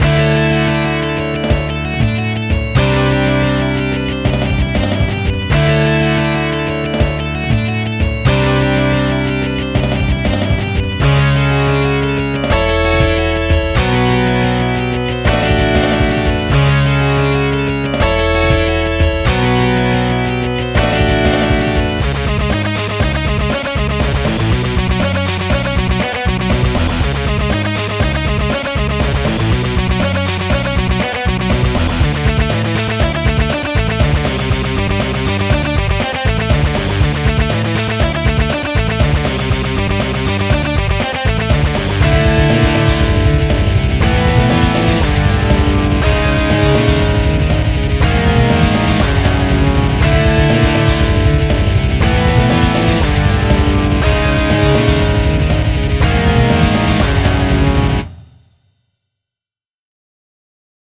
New and Improved Midi Song yay
Wow that's awesome, reminds me of something from an old Formula 1 Game I used to play.